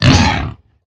assets / minecraft / sounds / mob / hoglin / hurt2.ogg
hurt2.ogg